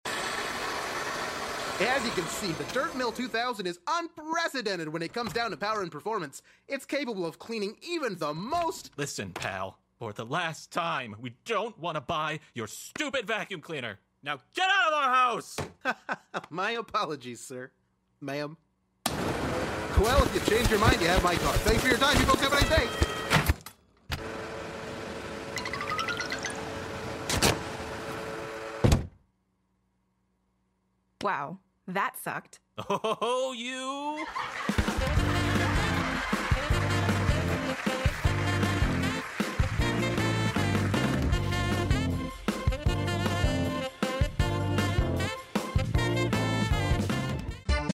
VACUUM sound effects free download